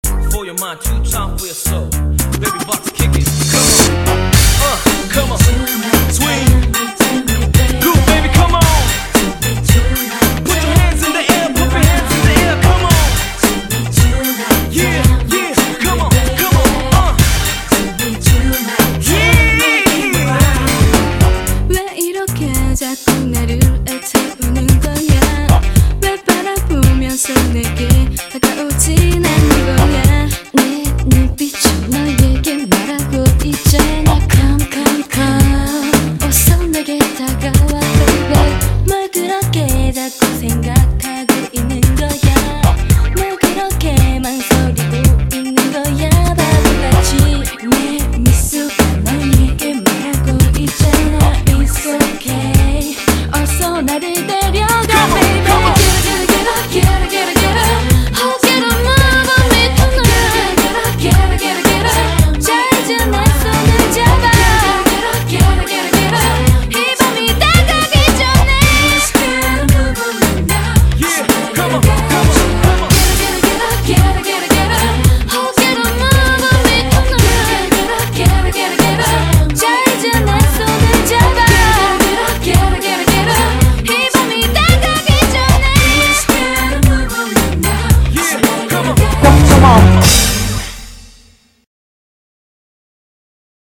BPM111--1
Audio QualityMusic Cut